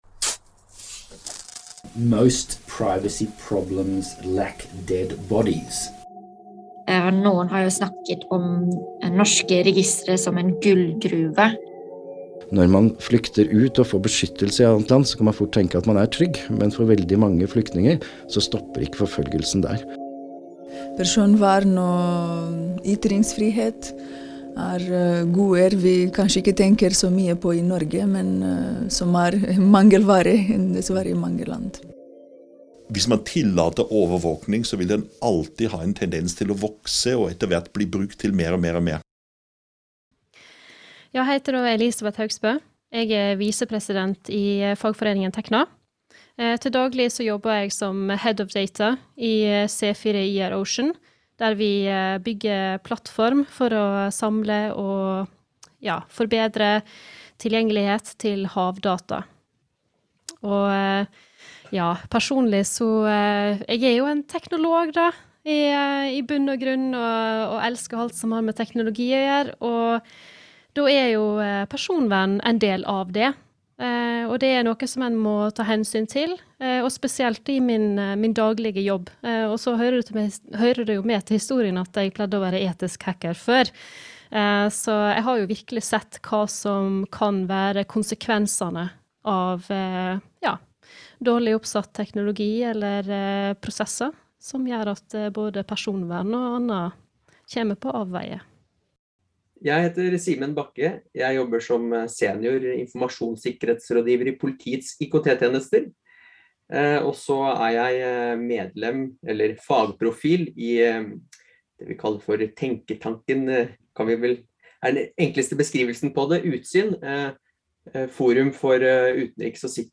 Her var det nok for programleder å putte på en femmer og sette samtalen i gang.